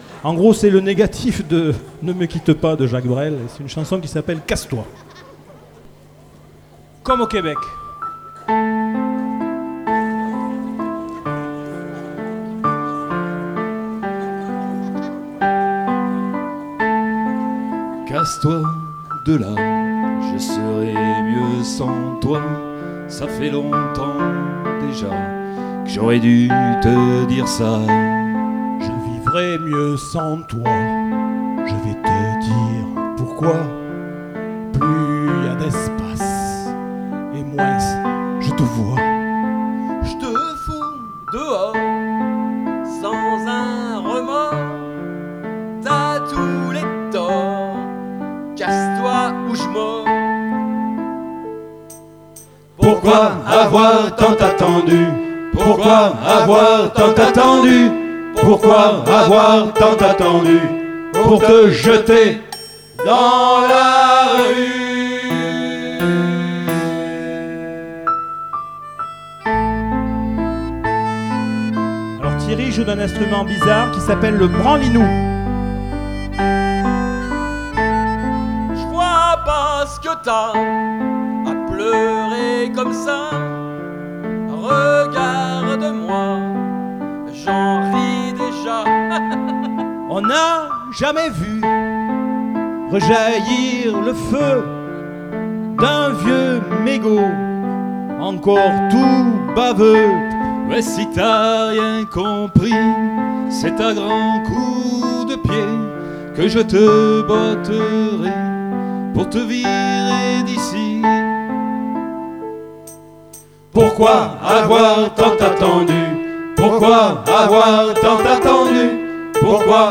enregistré  en public en 2011